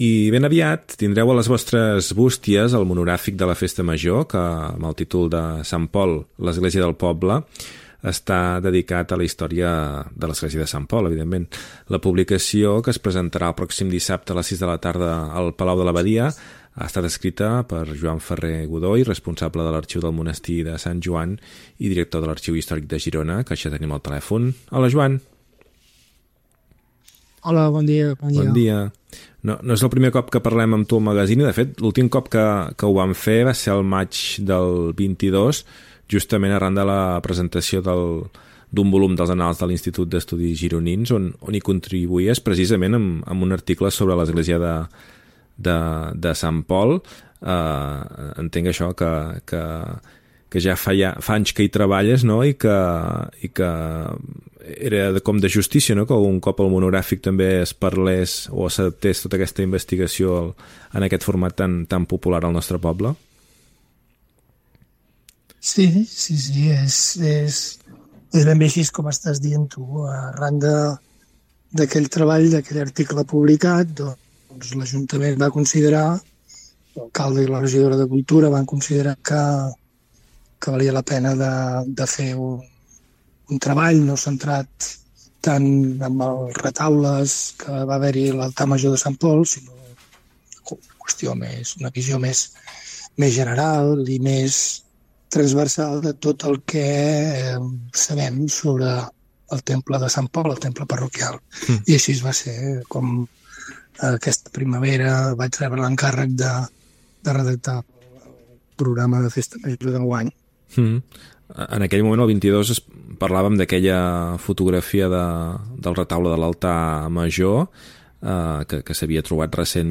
Pública municipal
Entreteniment
FM